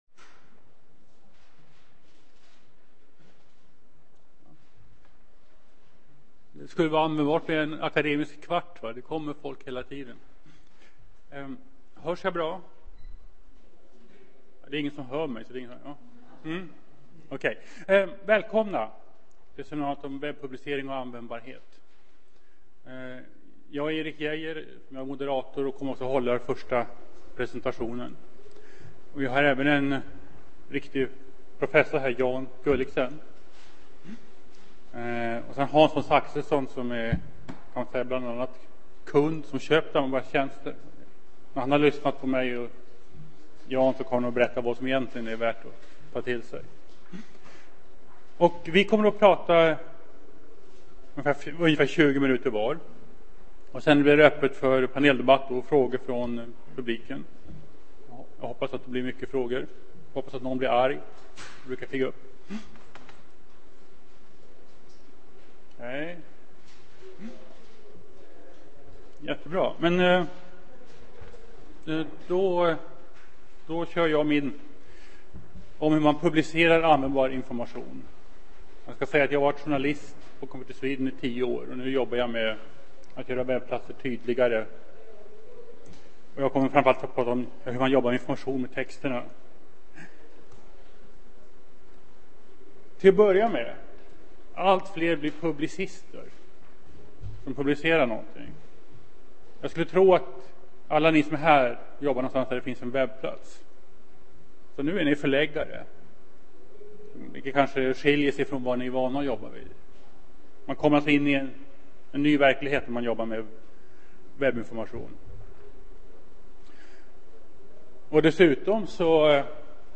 Hur ser vi till att den information som publiceras p� n�tet blir anv�ndbar? Hur b�r man b�st arbeta f�r att f� anv�ndarv�nliga webbplatser? I detta seminarium deltar en forskare, en konsult samt en best�llare med stor erfarenhet av att k�pa anv�ndbarhet.